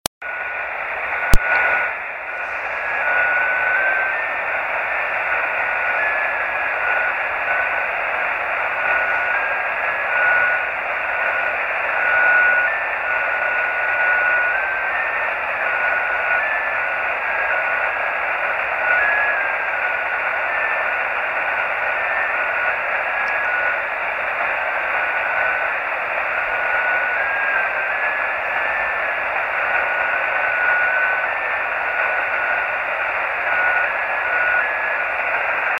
Grabaciones Rebote Lunar de KP4AO,
Radiotelescopio del Observatorio de Arecibo en Puerto Rico
Equipo: Yaesu FT-817.
Antena: Yagi 9 elementos para 432 MHz en boom de madera de 1 m de largo.
2-jt65.mp3